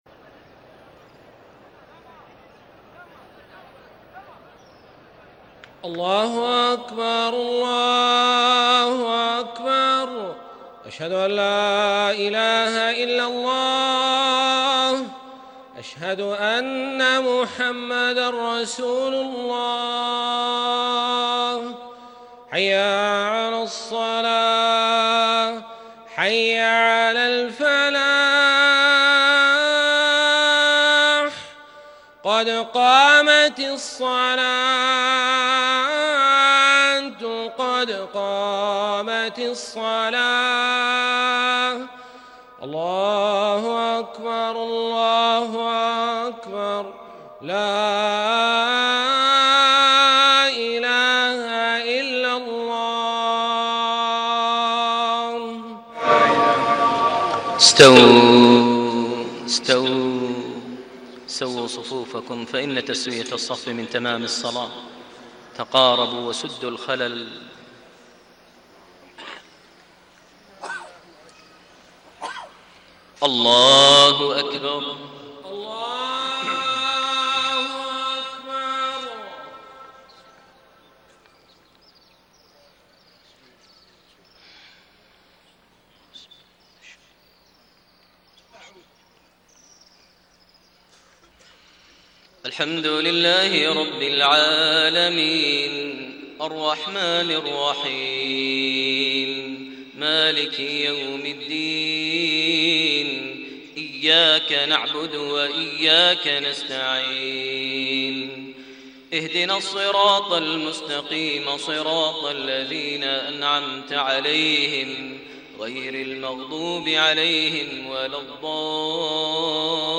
صلاة المغرب 8-3-1432 من سورة مريم 83-98 > 1432 هـ > الفروض - تلاوات ماهر المعيقلي